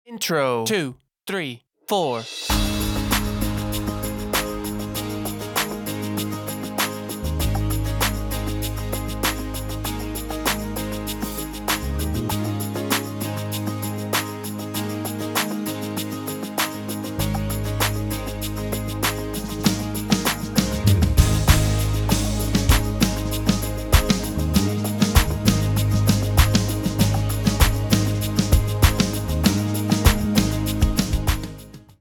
Key: D | Tempo: 97 BPM. https